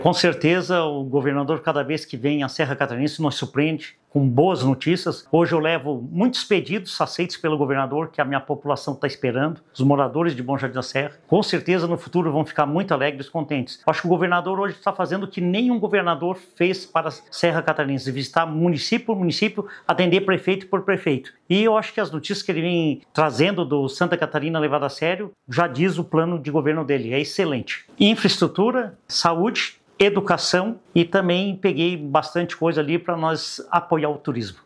Após a conversa individual com o governador Jorginho Mello, o prefeito de Bom Jardim da Serra, Pedro Luiz Ostetto, ressaltou que a cidade receberá investimentos em infraestrutura, saúde, educação e no turismo: